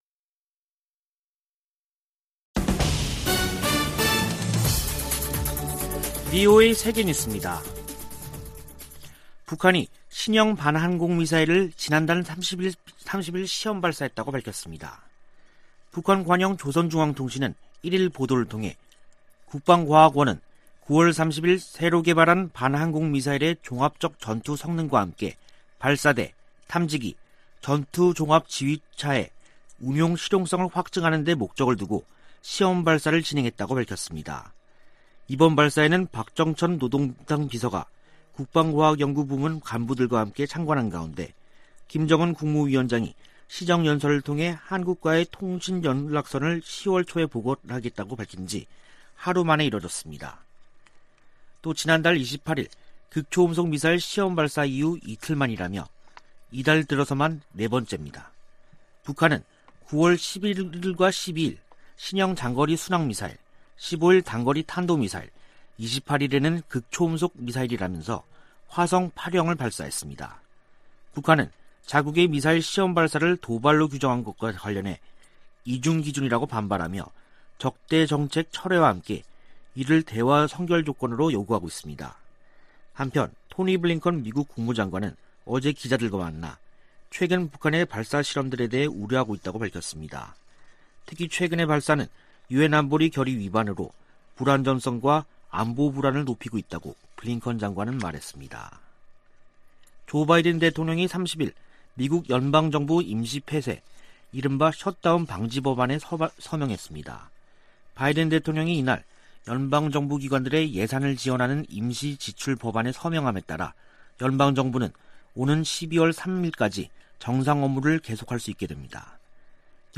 VOA 한국어 간판 뉴스 프로그램 '뉴스 투데이', 2021년 10월 1일 2부 방송입니다. 북한은 김정은 국무위원장이 남북 통신연락선 복원 의사를 밝힌 이튿날 신형 지대공 미사일을 시험발사했습니다. 토니 블링컨 미 국무장관은 북한의 극초음속 미사일 시험발사가 유엔 안보리 결의 위반이라고 지적했습니다. 미국의 전문가들은 김정은 위원장 시정연설에 대해 미국과 핵 문제를 협상할 의사가 없는 게 명백하다고 분석했습니다.